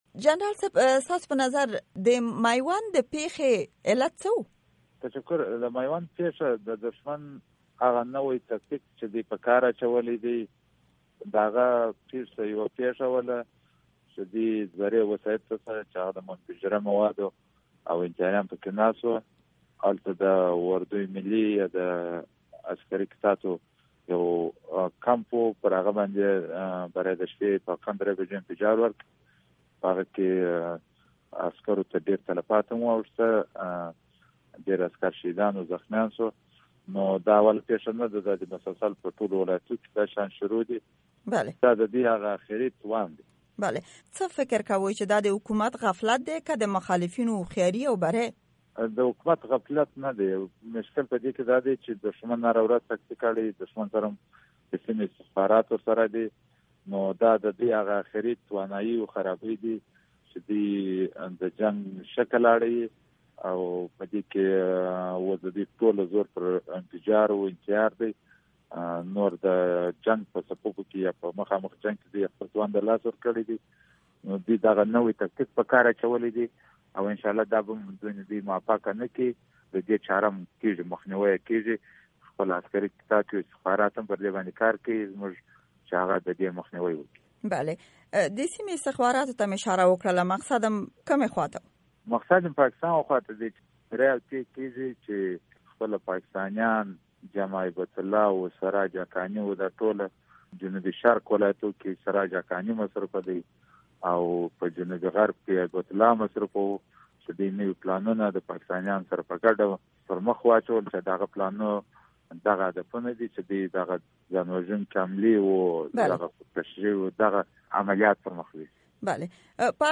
امريکا غږ سره د جنرال رازق مرکه